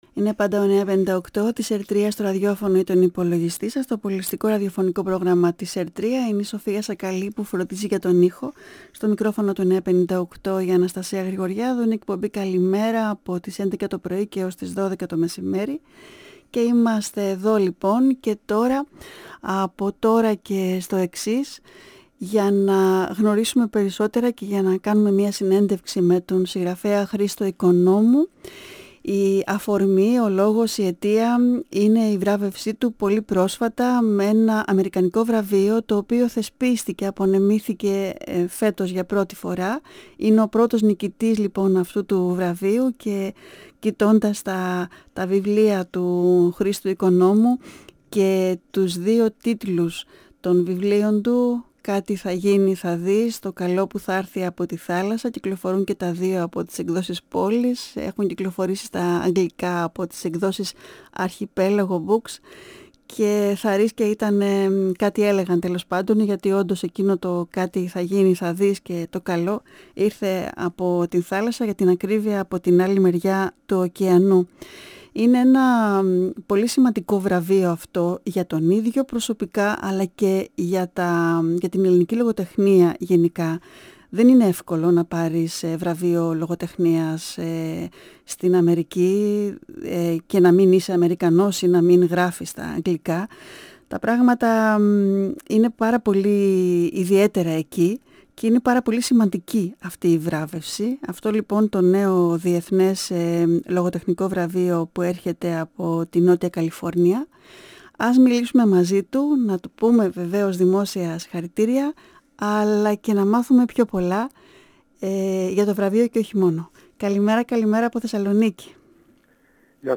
Η συνέντευξη πραγματοποιήθηκε τη Δευτέρα 20 Φεβρουαρίου 2022